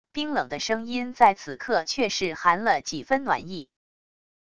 冰冷的声音在此刻却是含了几分暖意wav音频生成系统WAV Audio Player